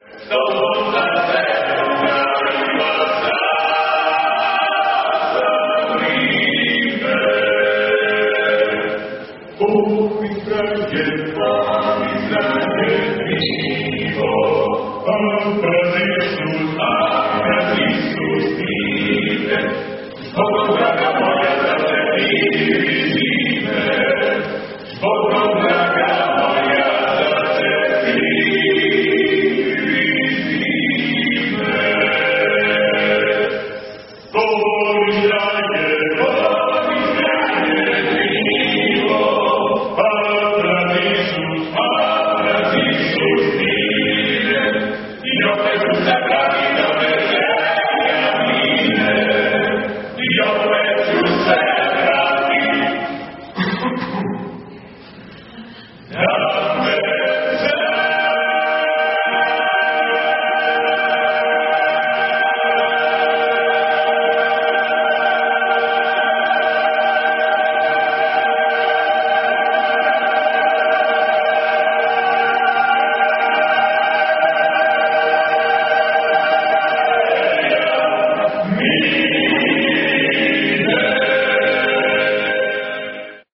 宮殿内で男声カルテットがCDの宣伝のために歌を披露してい た. 宮殿内の反響も手伝って, なかなかよくハモっていた.
右から二人目のたぶんバリトンの人がリーダーらしくて, CDを手にしている.
カルテット